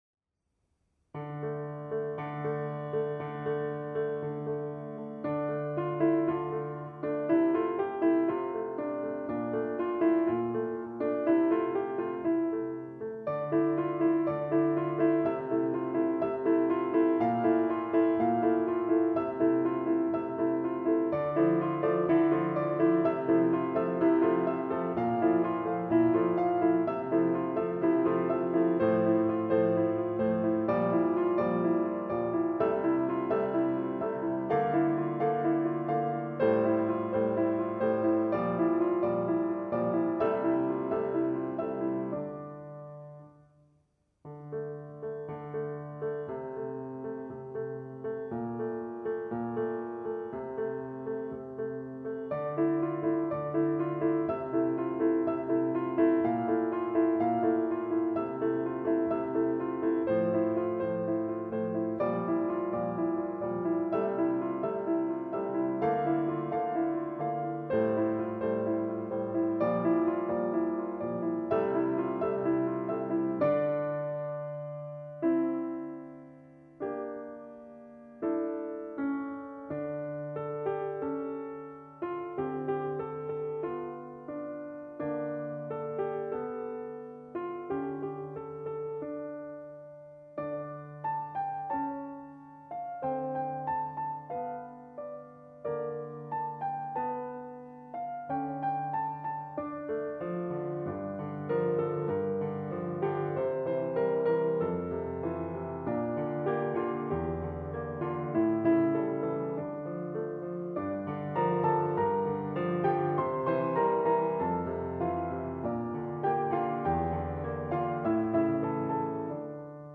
Genre :  ChansonComptine
Style :  Avec accompagnement
Enregistrement piano seul